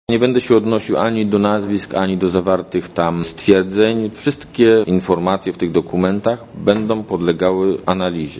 Obecny na konferencji, szef gdańskiej prokuratury apelacyjnej Janusz Kaczmarek powiedział, że żadnego z dokumentów nie wytworzyło CBŚ.
Mówi prokurator apelacyjny